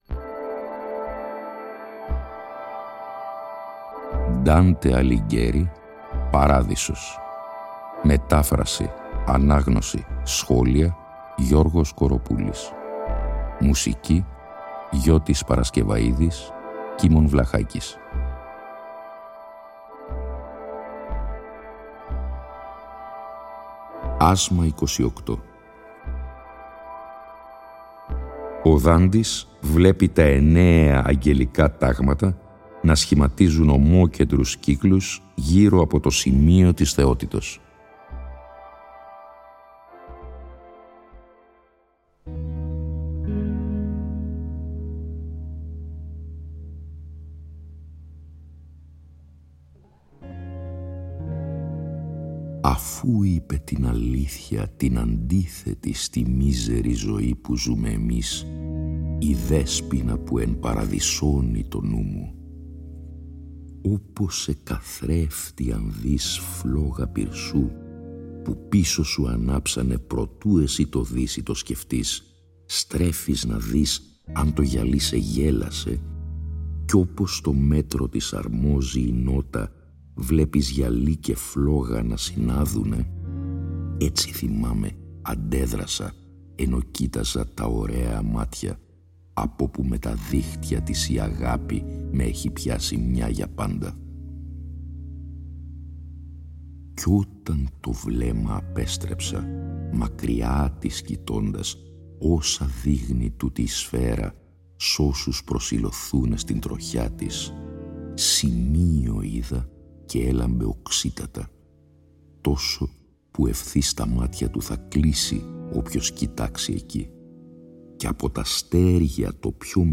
Η ανάγνωση των 33 ασμάτων του «Παραδείσου», σε 21 ημίωρα επεισόδια, (συνέχεια της ανάγνωσης του «Καθαρτηρίου», που είχε προηγηθεί) συνυφαίνεται και πάλι με μουσική